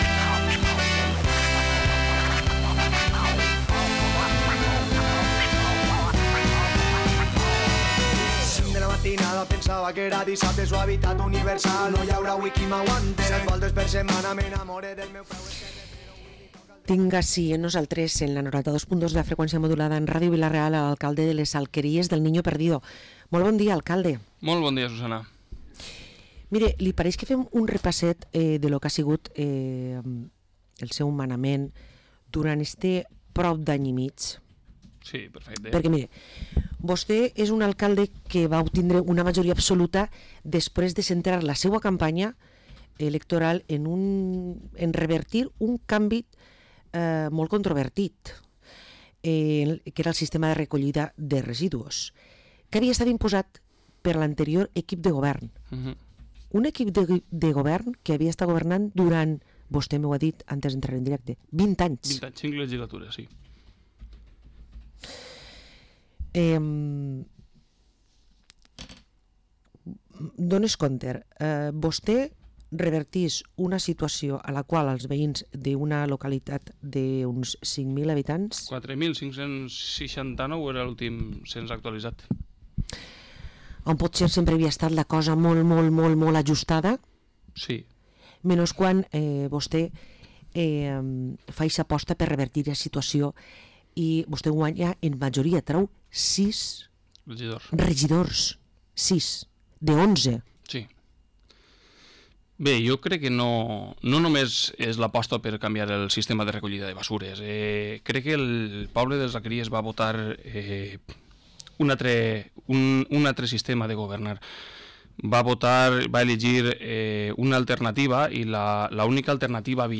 Parlem amb l´Alcalde de les Alqueries, Antonio Gil